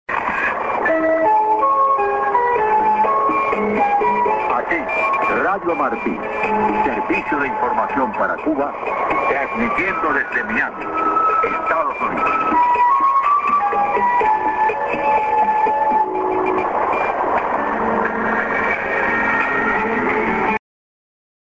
->ST->ID(man)->